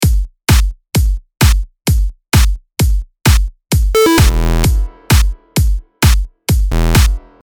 DTM講座　Electro House （エレクトロ ハウス）の作り方④